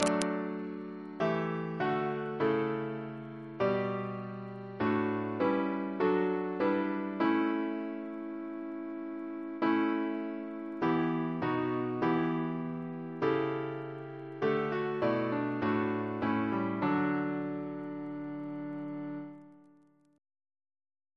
Double chant in D Composer: Thomas Attwood (1765-1838), Organist of St. Paul's Cathedral Reference psalters: ACP: 226; PP/SNCB: 177